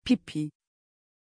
Pronunciation of Pippi
pronunciation-pippi-tr.mp3